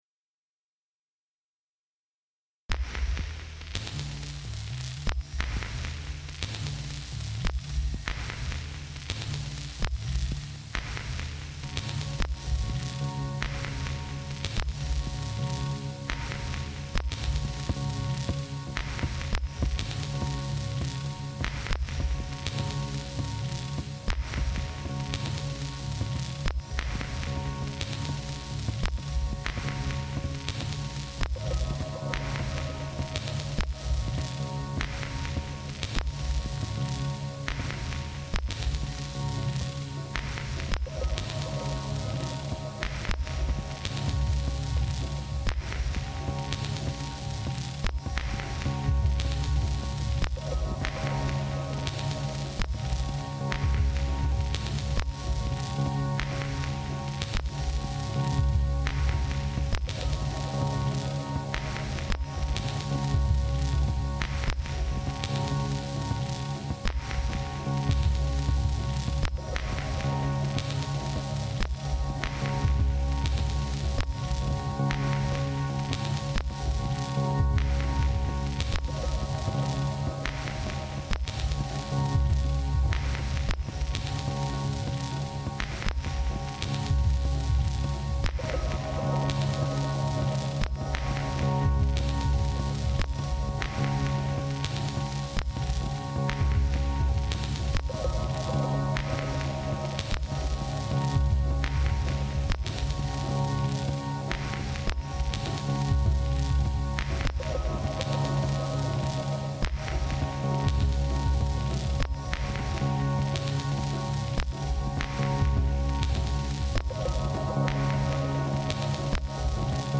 abstracto pero rico en texturas.